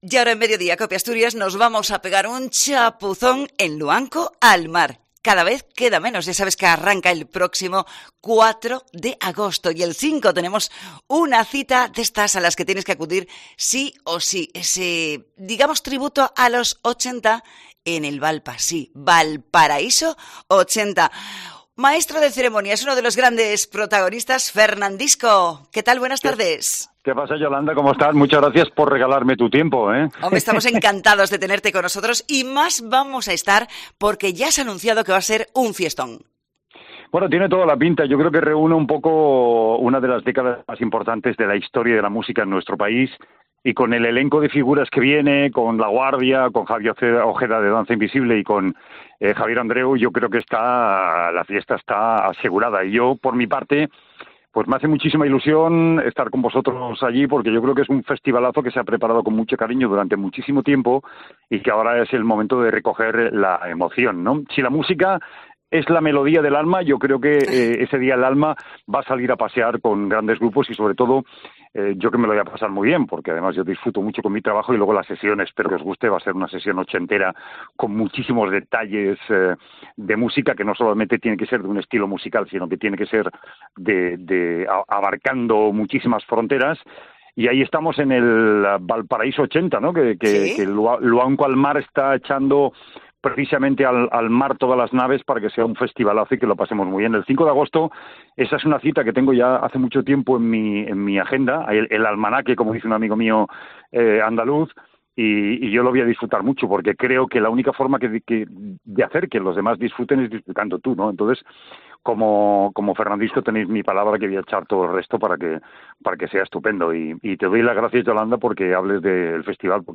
Entrevista a Fernandisco en COPE Asturias